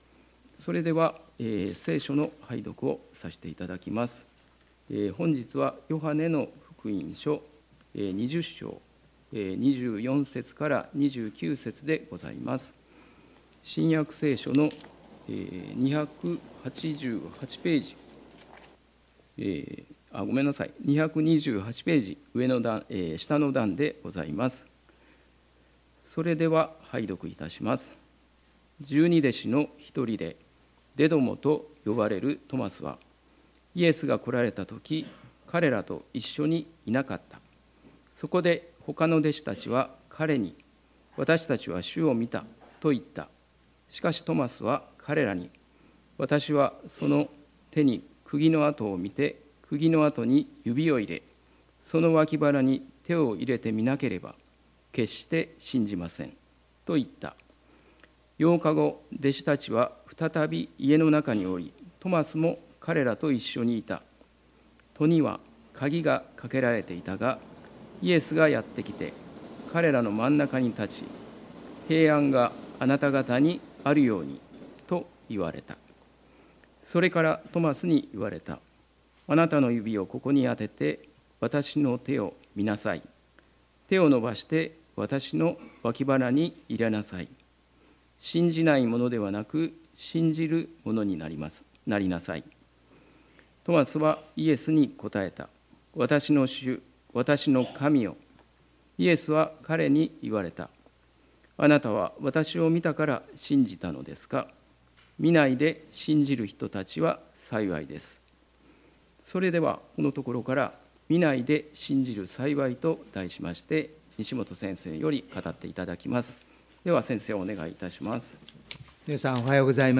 礼拝メッセージ「見ないで信じる幸い」│日本イエス・キリスト教団 柏 原 教 会